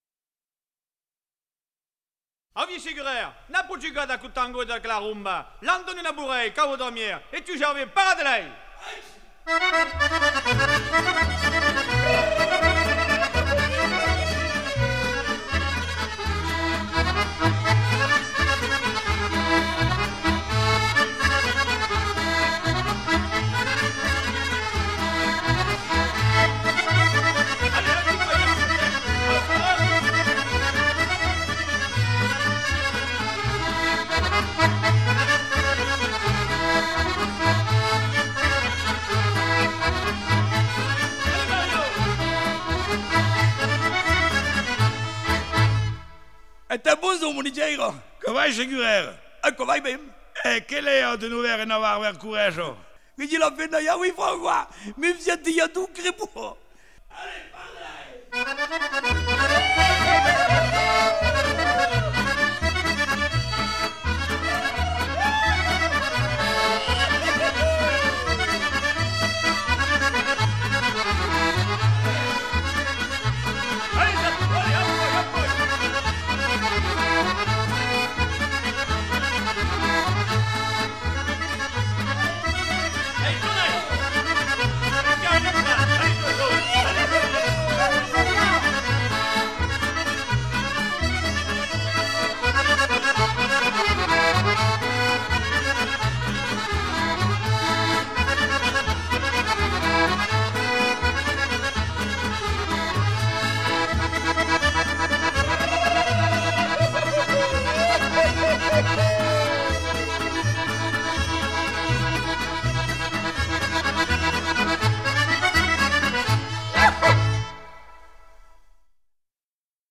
Musique traditionnelle d'Auvergne (séance 2)
02a_BourréesAccordeonDiatonique.mp3